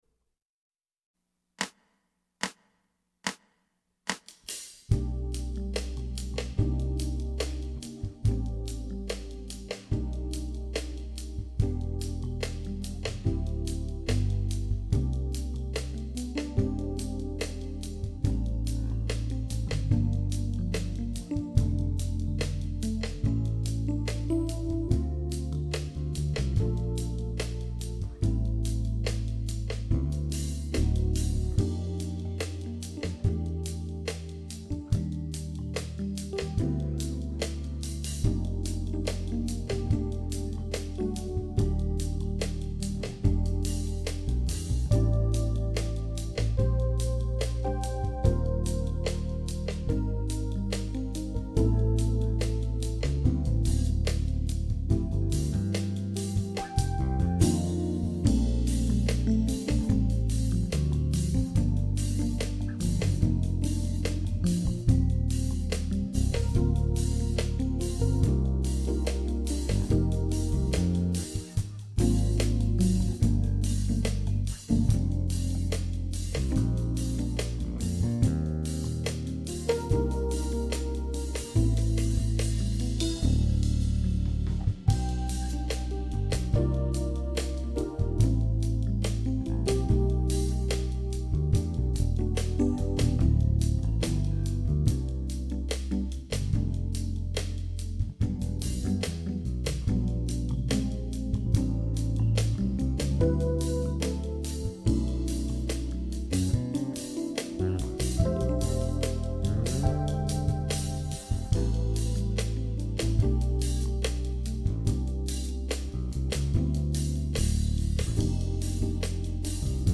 TROMBA SOLO • ACCOMPAGNAMENTO BASE MP3
Trombone